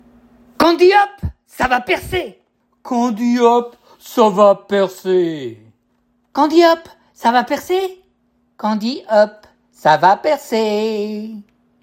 36 - 60 ans - Ténor